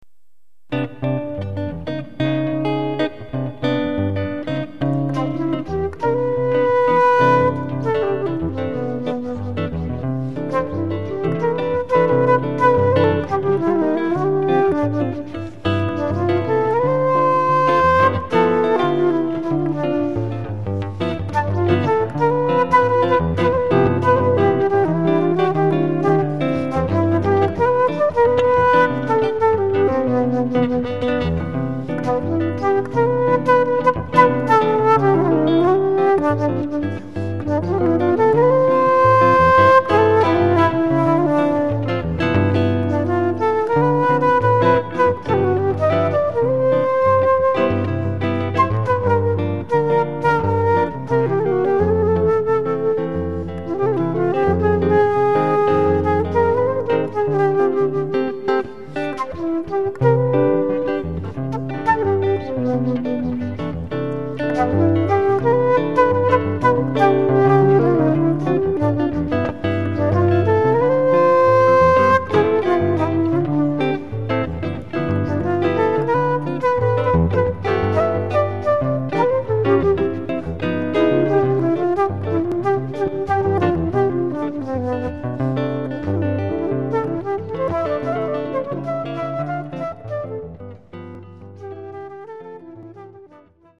Guitar/Flute Duo